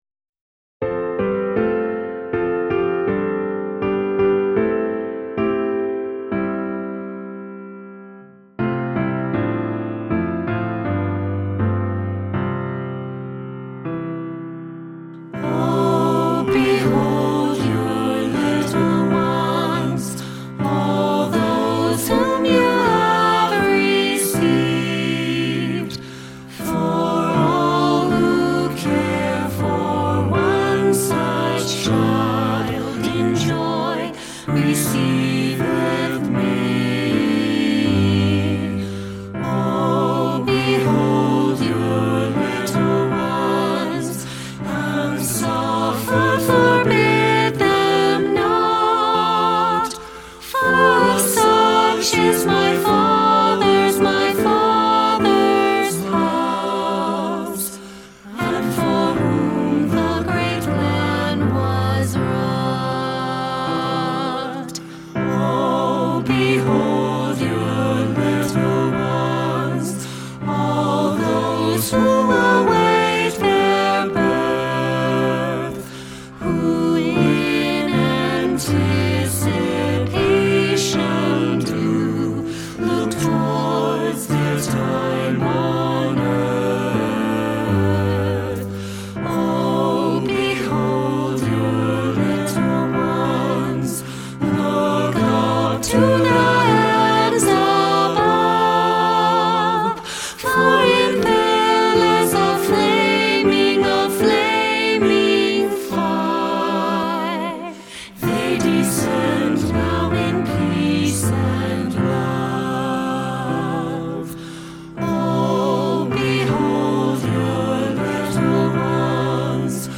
SATB Hymn
SATB Traditional Hymn